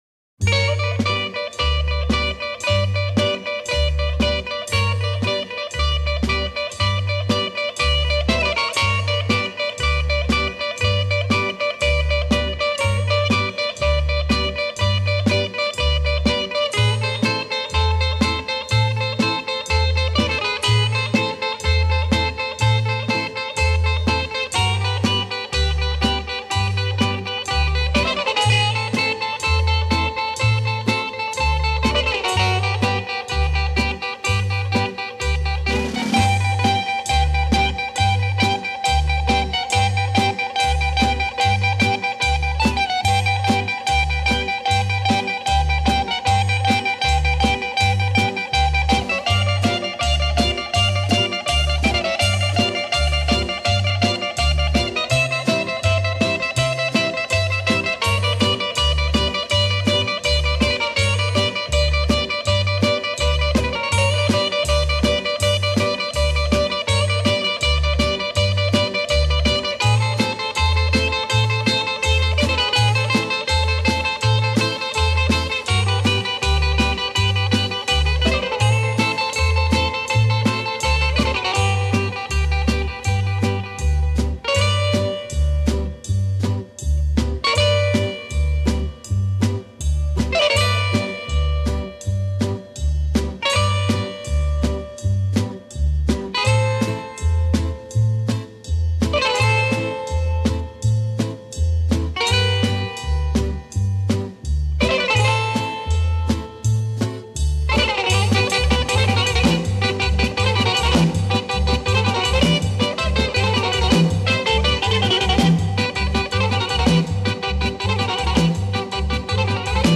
Если угодно, то и сиртаки